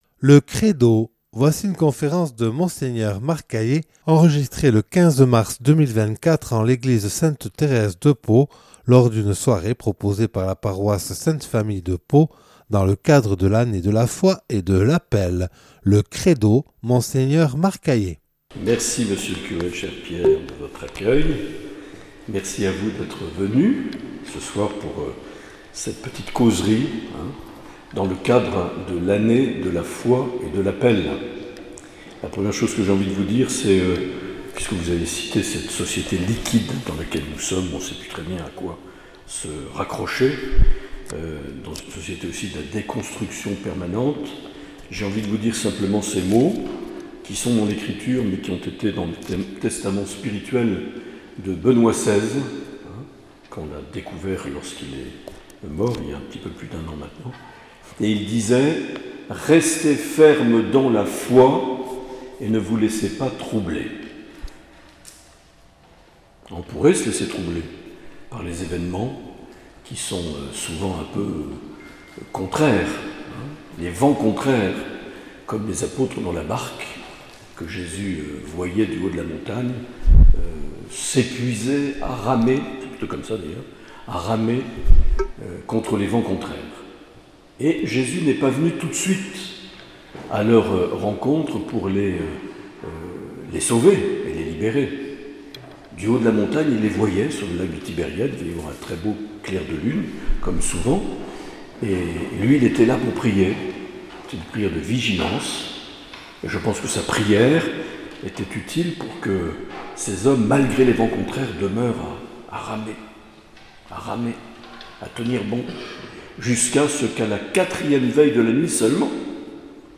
Conférence de Mgr Marc Aillet. (Enregistrée le 13/03/2024 en l’église Sainte Thèrese de Pau lors d’une soirée proposée par la paroisse de la Sainte-Famille pour l’Année de la foi et de l’appel)